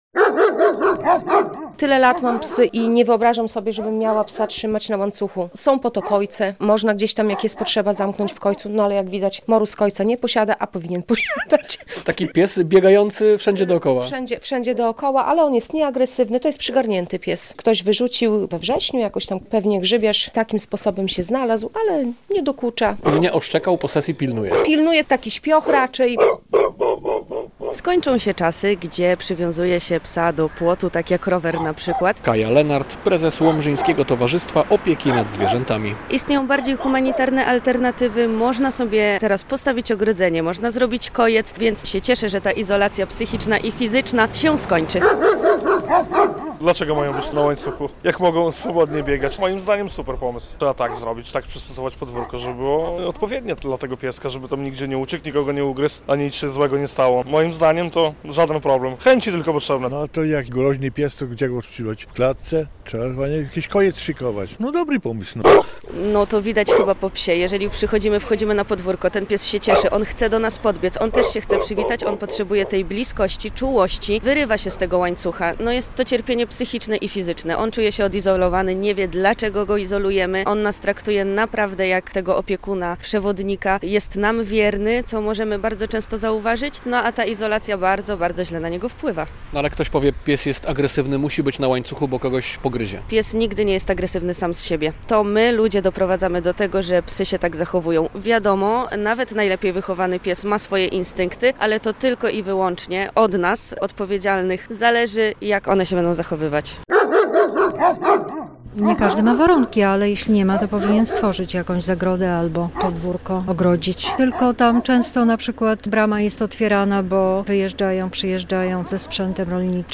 A co o tym sądzą mieszkańcy Łomży i okolicznych miejscowości?